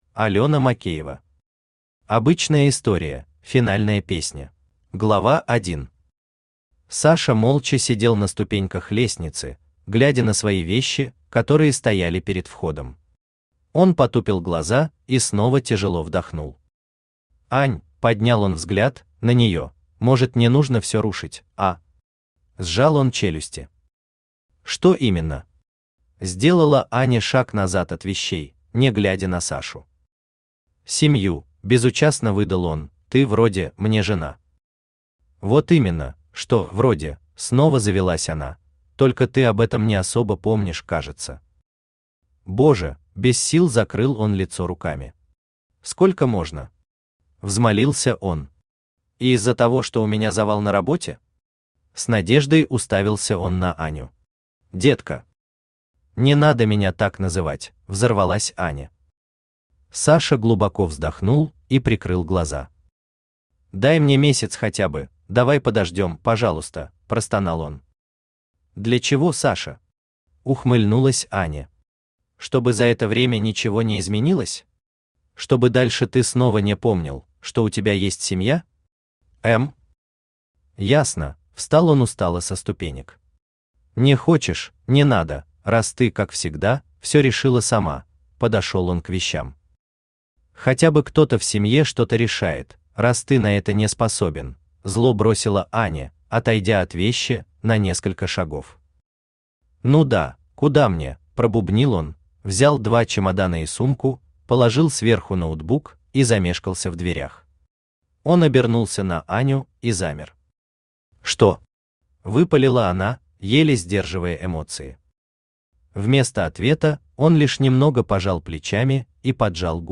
Аудиокнига Обычная история: финальная песня | Библиотека аудиокниг
Aудиокнига Обычная история: финальная песня Автор Алёна Макеева Читает аудиокнигу Авточтец ЛитРес.